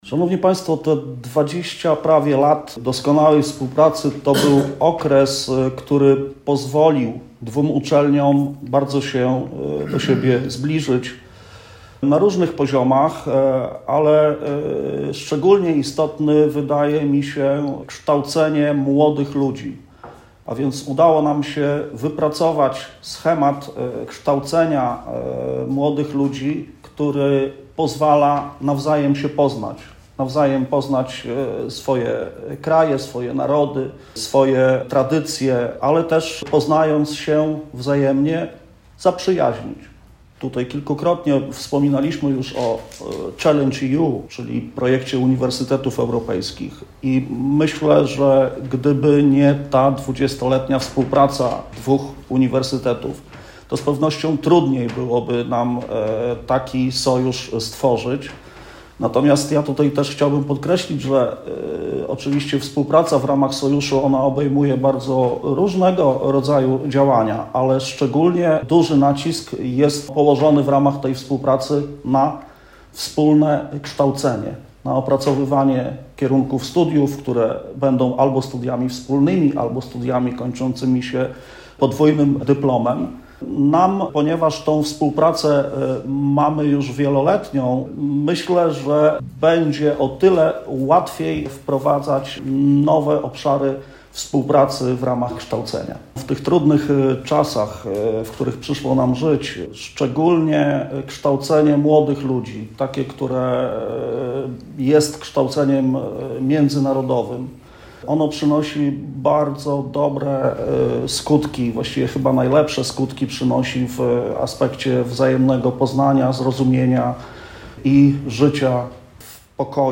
Posłuchajcie relacji z kolejnego spotkanie, które przed laty określono mianem Mostu Bożonarodzeniowego.